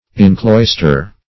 Search Result for " incloister" : The Collaborative International Dictionary of English v.0.48: Incloister \In*clois"ter\, v. t. [Pref. in- in + cloister: cf. F. enclo[^i]trer.